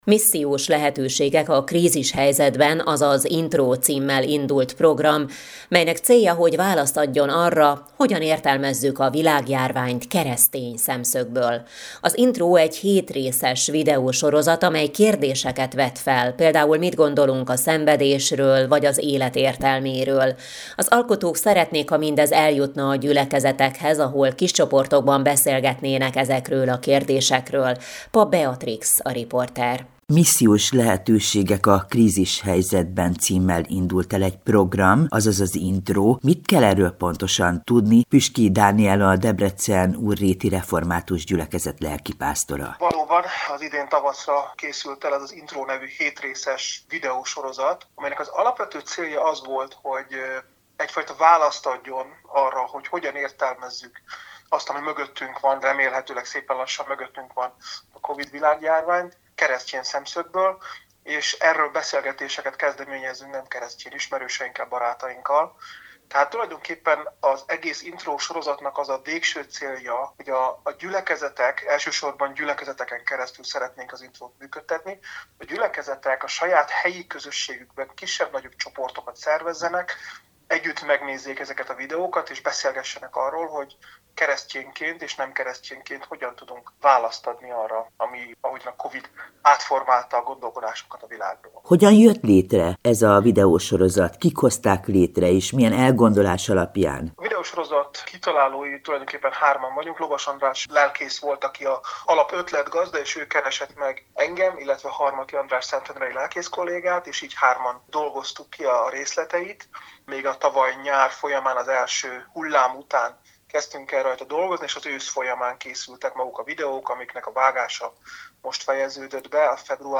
az Európa Rádióban